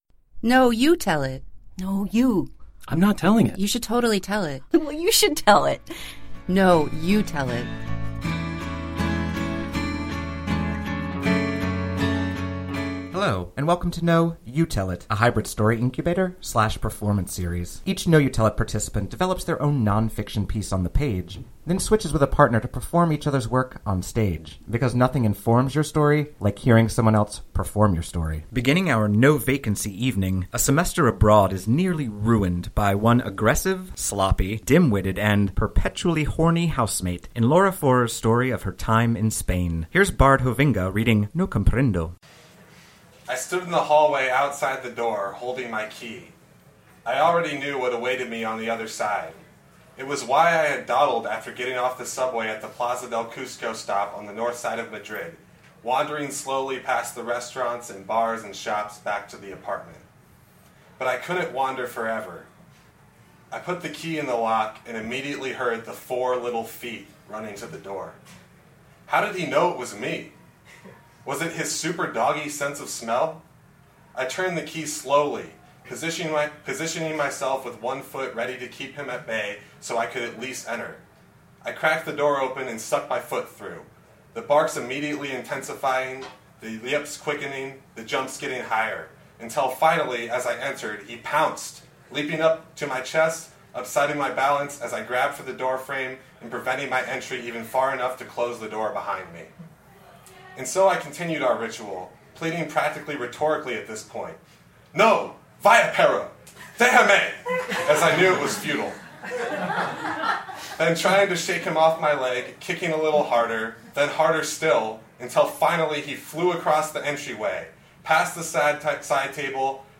live show | No, YOU Tell It!